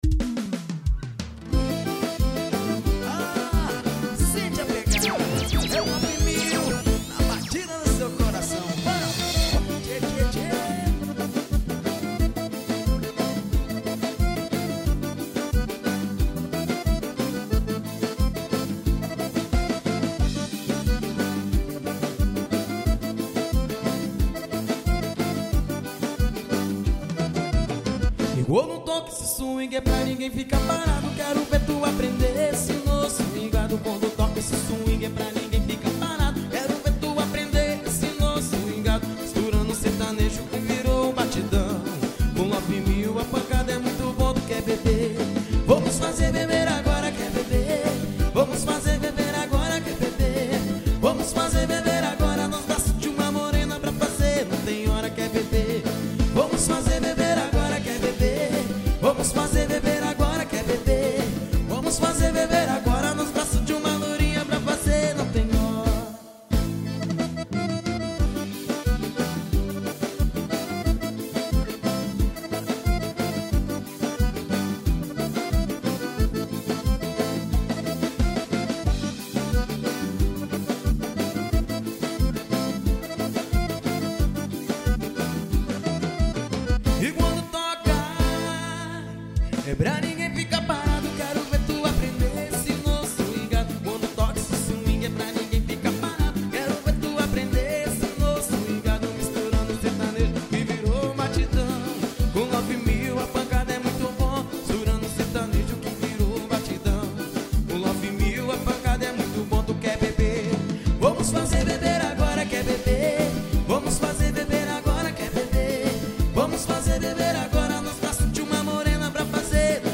pegada sertaneja.